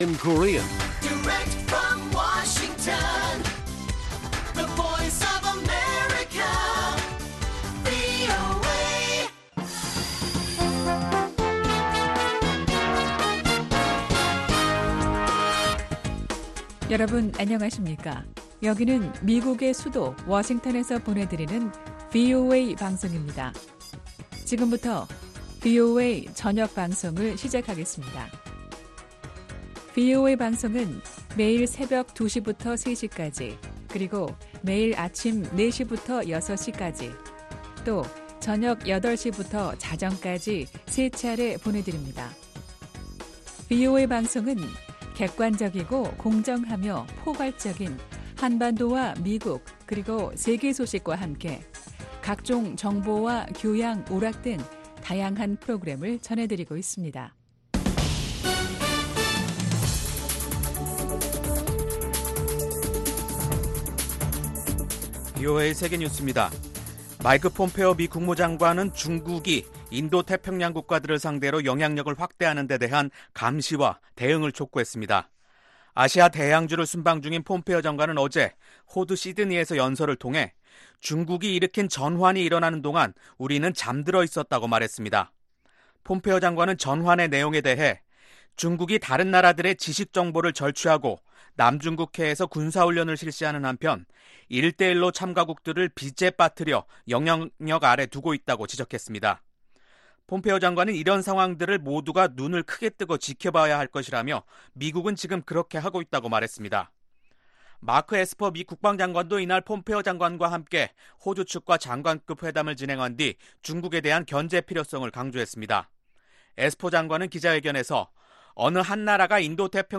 VOA 한국어 간판 뉴스 프로그램 '뉴스 투데이', 2019년 8월 5일1부 방송입니다. 미군과 한국군의 연합군사훈련, 지휘소연습이 시작됐습니다.